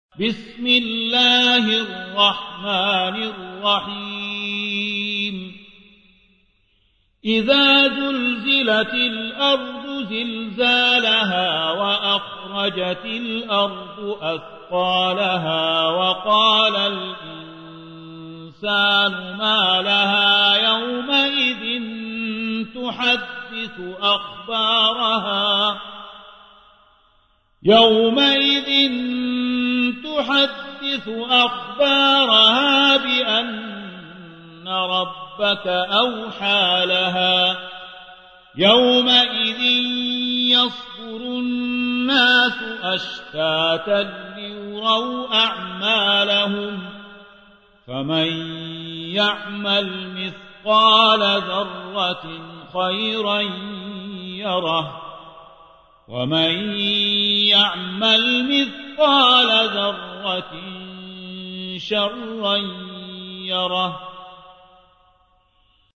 99. سورة الزلزلة / القارئ